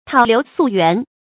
討流溯源 注音： ㄊㄠˇ ㄌㄧㄨˊ ㄙㄨˋ ㄧㄨㄢˊ 讀音讀法： 意思解釋： 謂深究事物的源流本末。